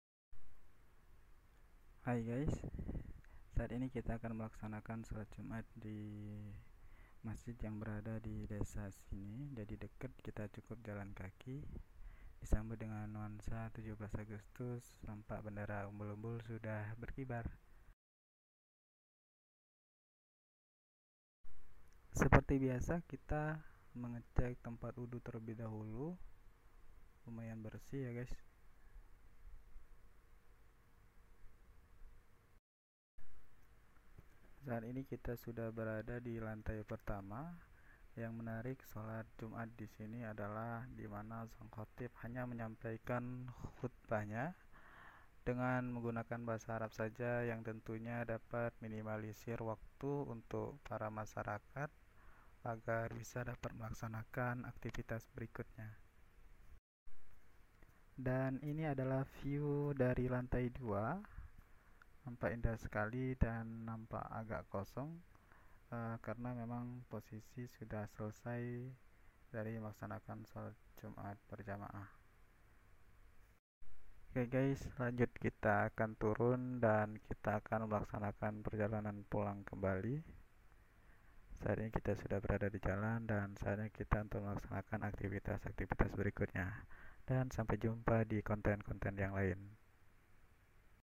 vlog Jum'atan ku Masjid Desa Pasir Nangka Tigaraksa Tangerang Banten